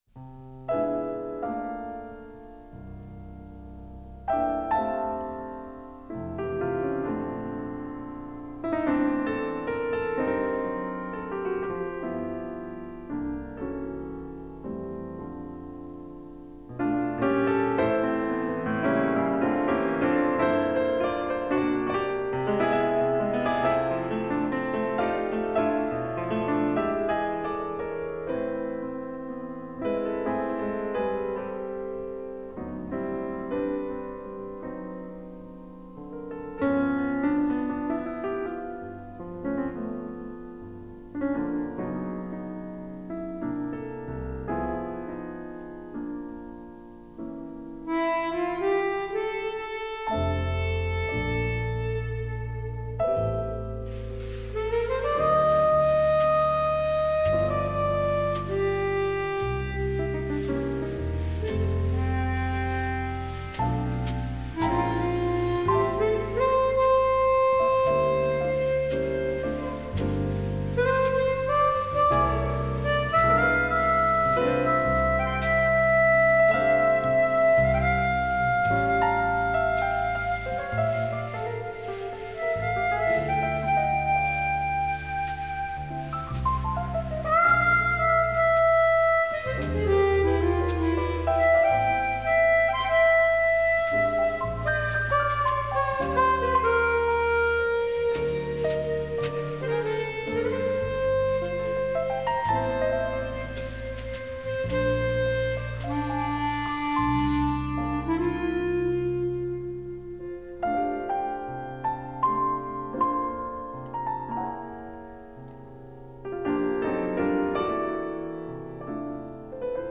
piano
harmonica.